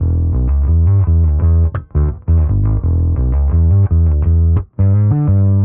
Index of /musicradar/dusty-funk-samples/Bass/85bpm
DF_PegBass_85-E.wav